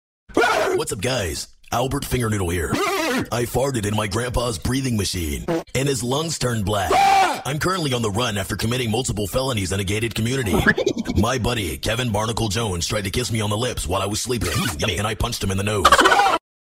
Finger Sound Effects MP3 Download Free - Quick Sounds